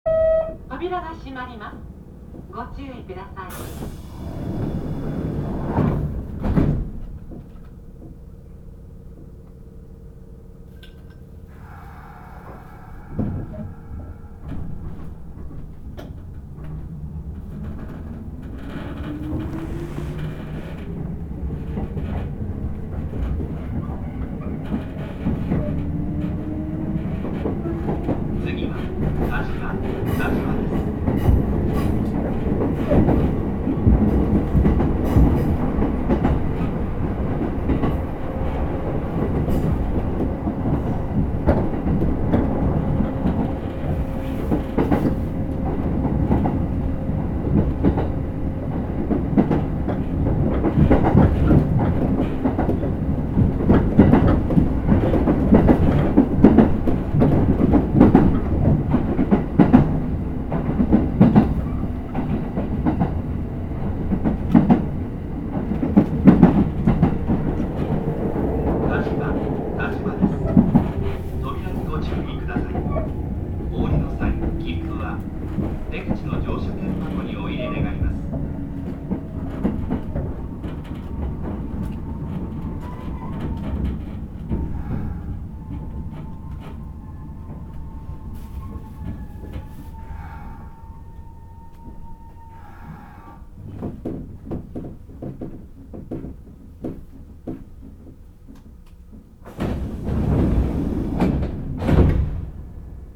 走行音
録音区間：黒田～但馬(お持ち帰り)